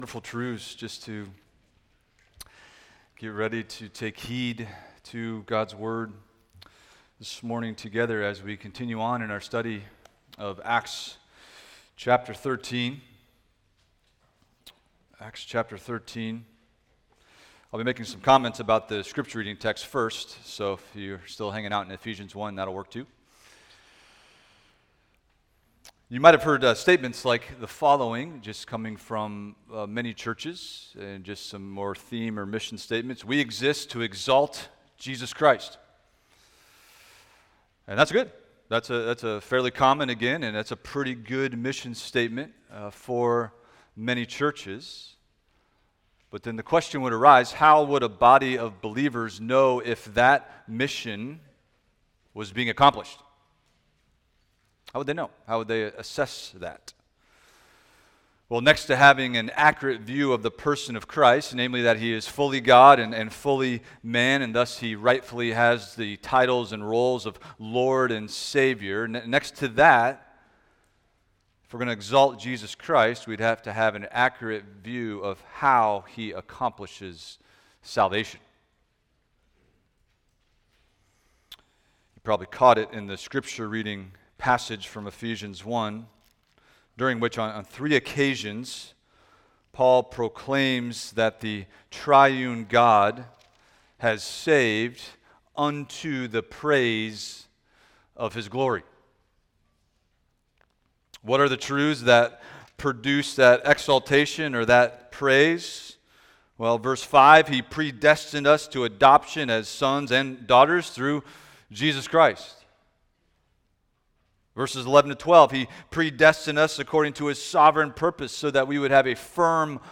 Expository Preaching through the Book of Acts